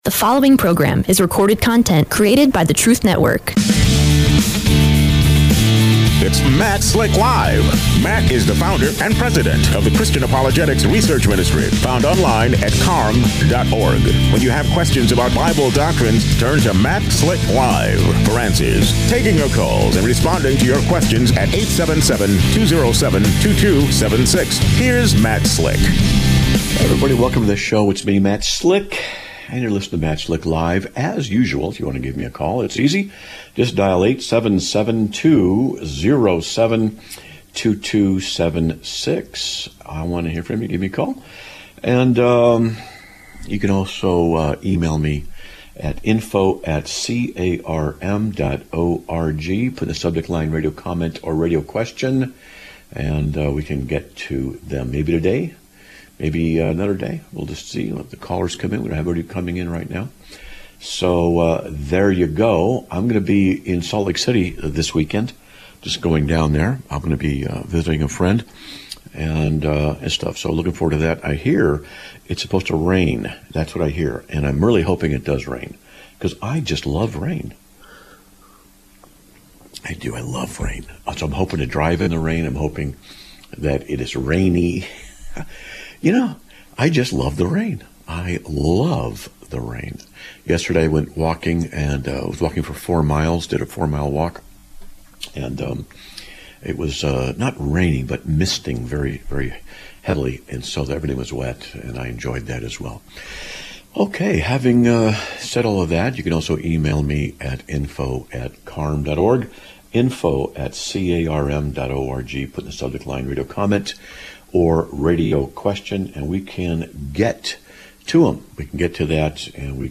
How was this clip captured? Live Broadcast of 10/03/2025